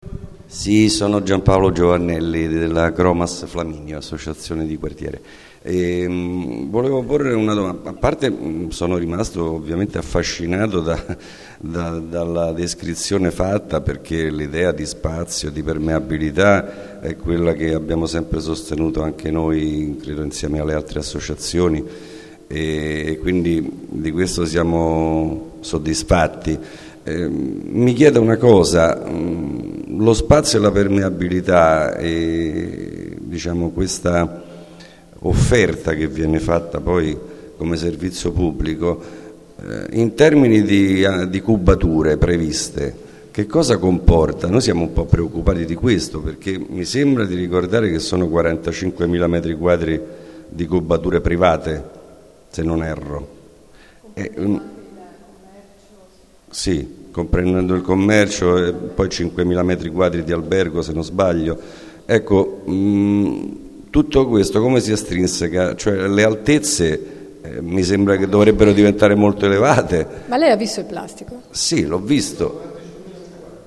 Piano di recupero del Quartiere Città della Scienza - Ascolto audio dell'incontro